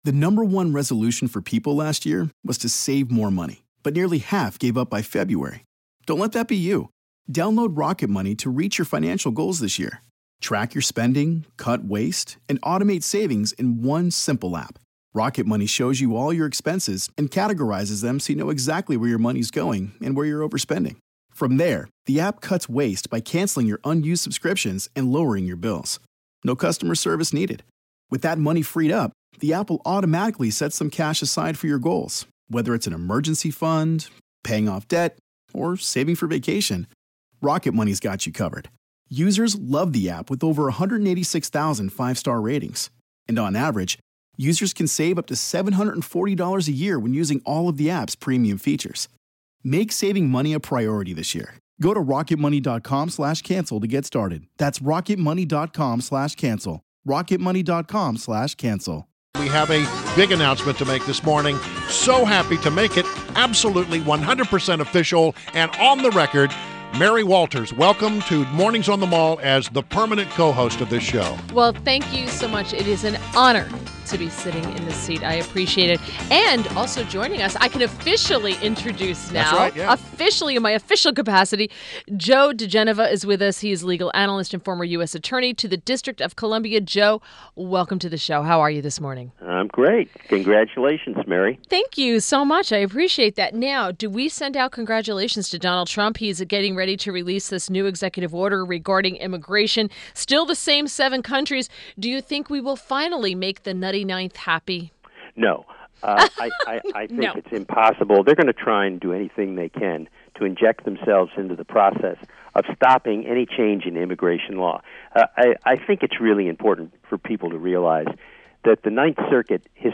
WMAL Interview - JOE DIGENOVA - 02.21.17
INTERVIEW – JOE DIGENOVA – legal analyst and former U.S. Attorney to the District of Columbia